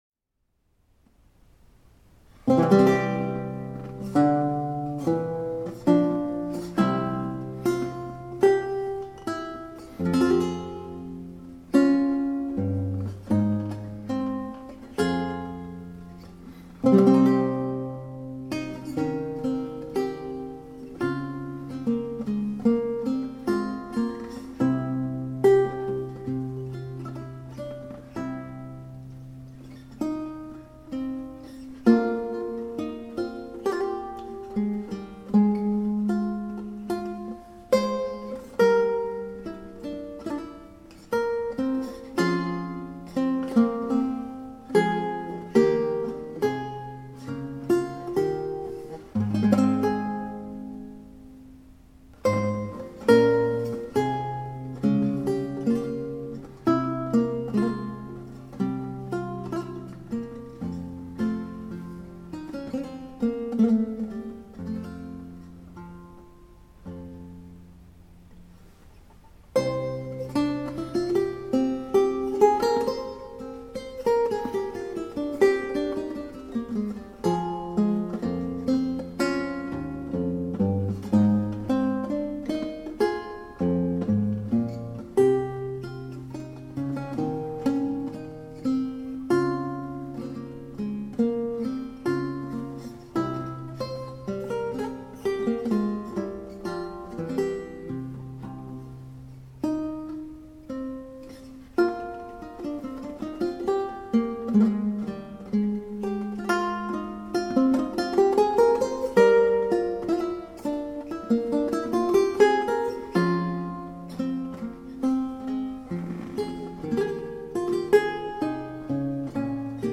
Note on the recordings:  the same seven-course lute (string length 673mm, made in 1982) was used for both the recordings.
Written for a seven-course lute.